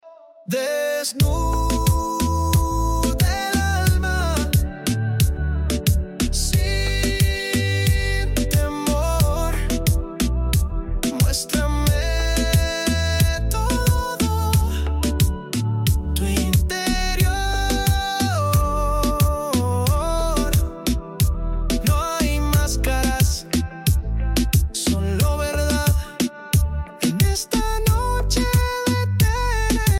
Un brano profondo, romantico, che tocca l’anima.